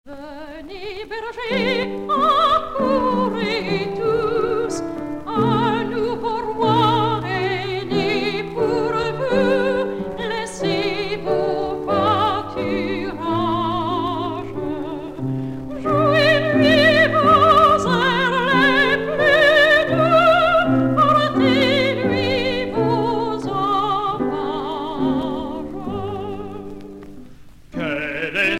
Noël
Genre strophique
Pièce musicale éditée